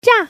Index of /qixiGame/release/guanDan/jsGuangDian/assets/res/zhuandan/sound/woman/